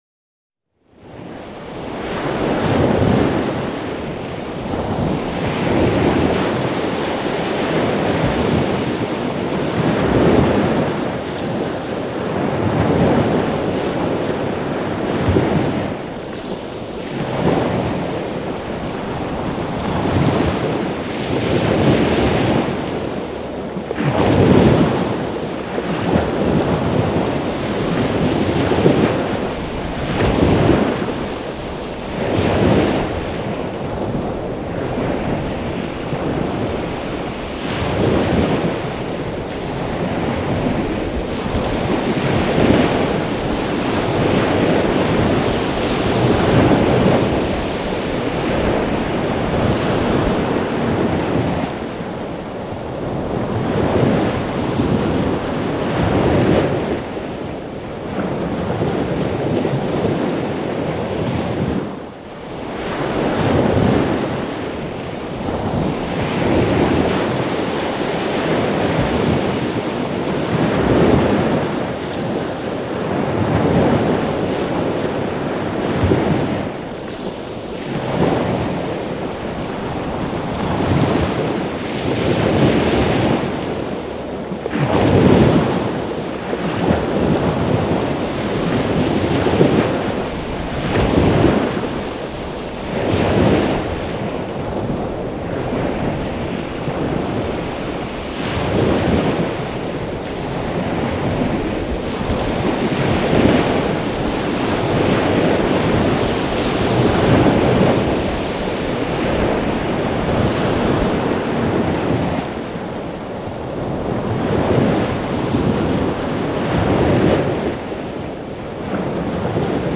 the-sea.mp3